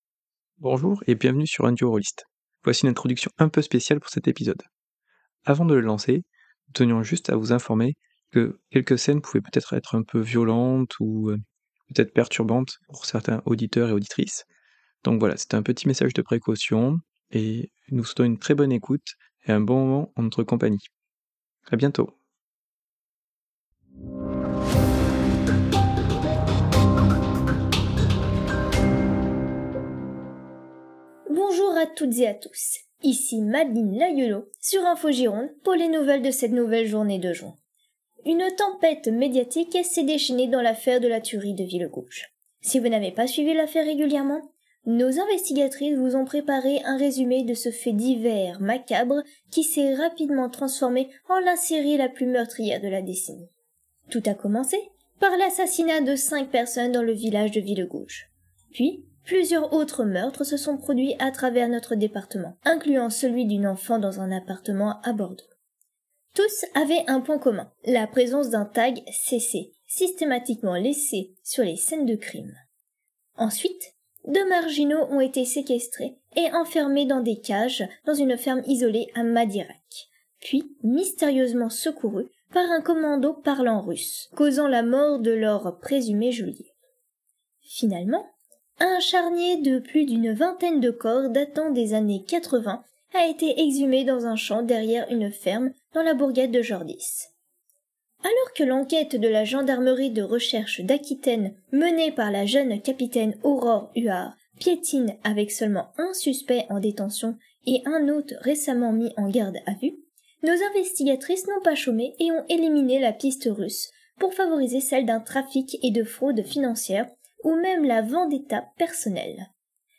Kicking/Forcing/Breaking Wooden Door
9mm Gunshots 1
AR15 rifle shot
Glass Broken